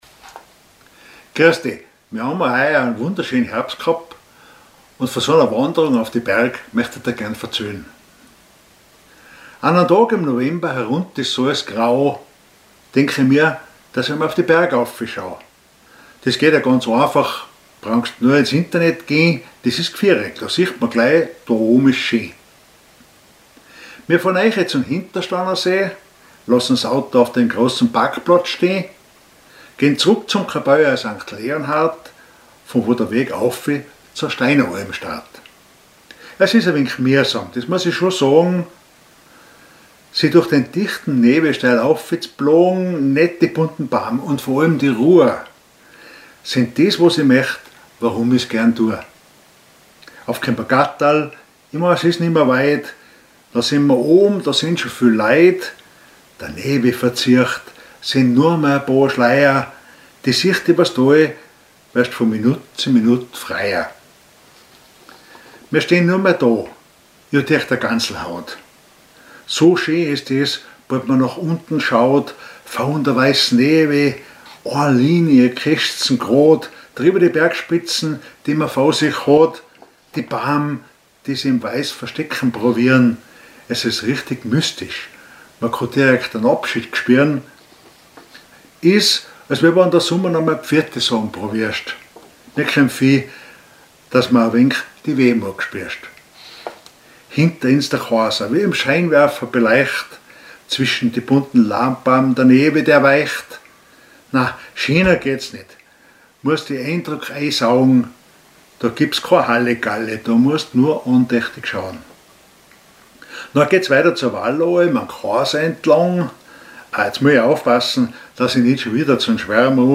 Gedicht Jänner 2025
Type: gefühlvoll